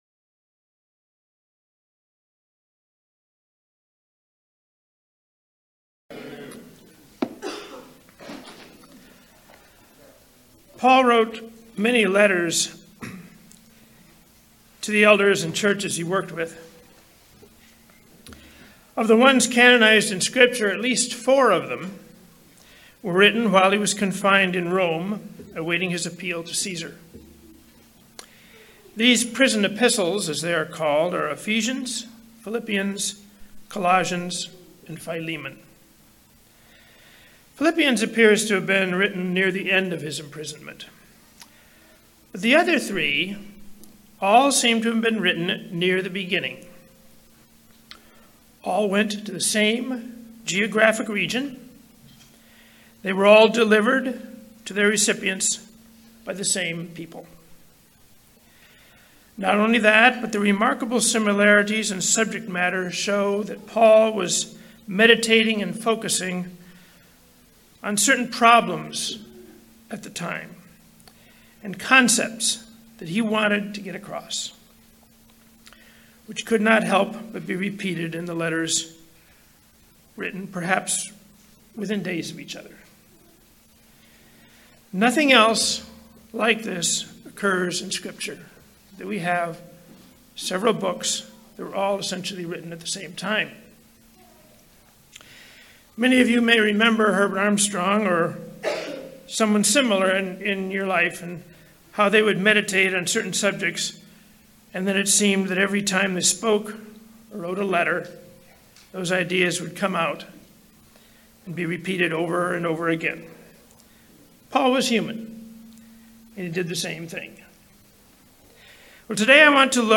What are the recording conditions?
Given in Las Vegas, NV Redlands, CA San Diego, CA